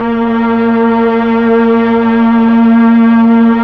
Index of /90_sSampleCDs/Roland LCDP09 Keys of the 60s and 70s 1/PAD_Melo.Str+Vox/PAD_Tron Str+Vox